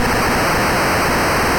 jetpack.ogg